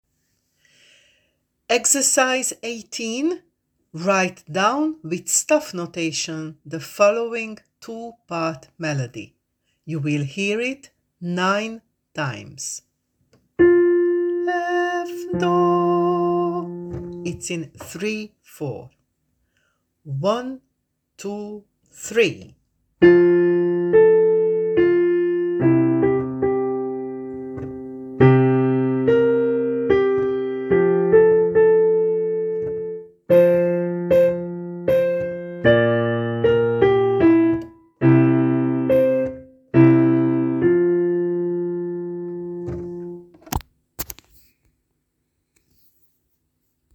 18. Write down with staff notation the following two-part melody. You will hear it 9 times: